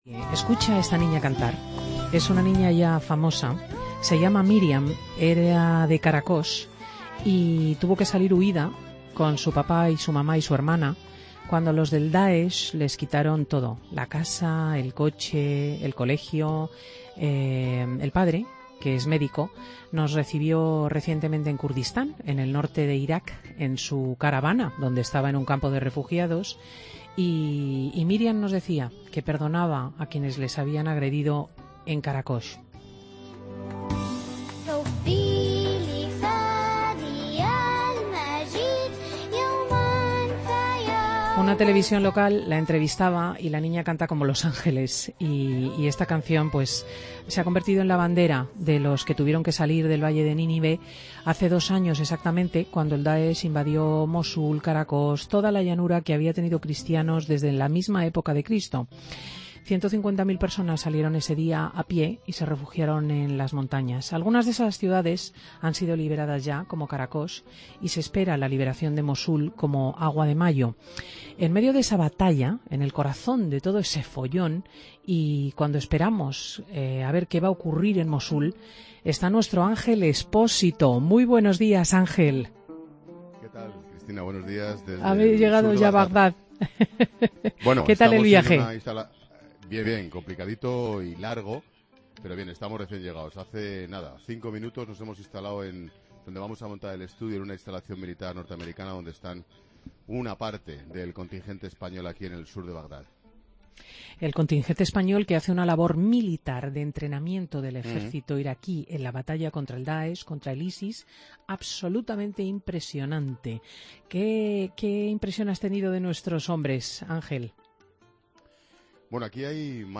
Cristina charla con Ángel Expósito que se encuentra en Bagdad para realizar 'La Tarde' y contar desde allí cómo se combate contra el Daesh en Irak